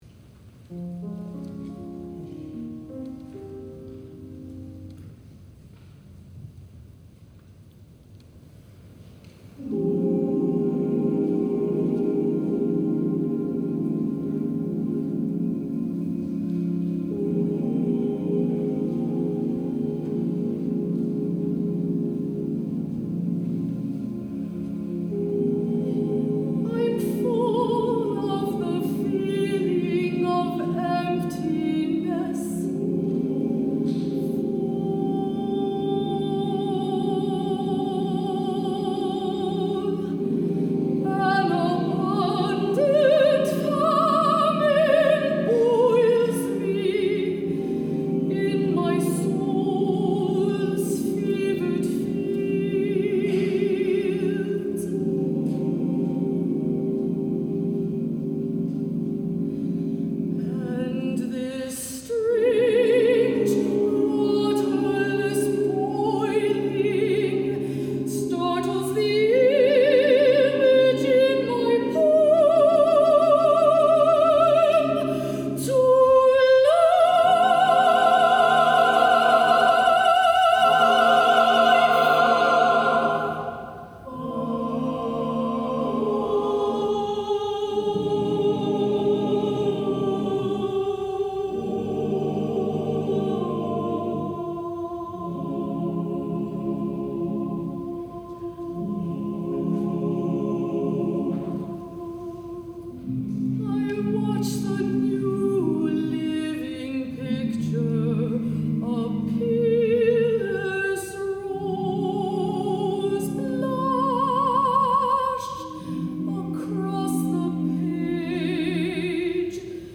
mezzo-soprano solo, SATB double choir